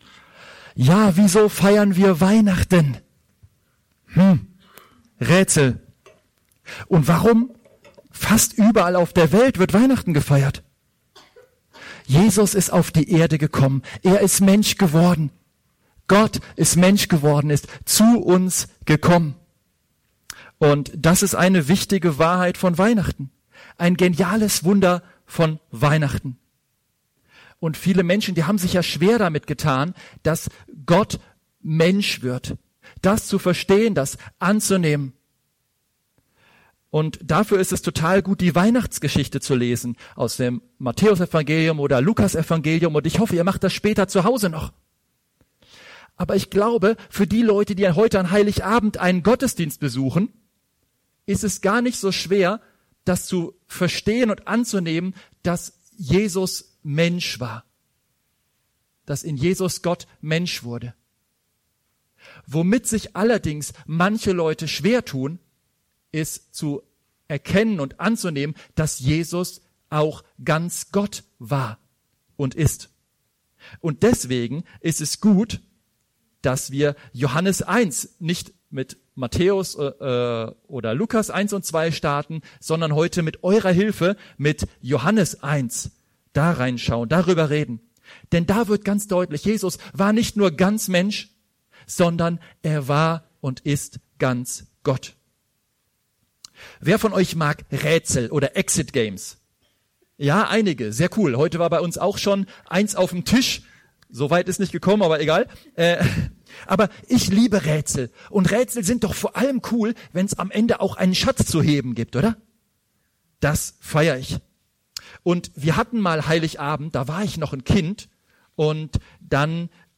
Aktuelle Predigten & Vorträge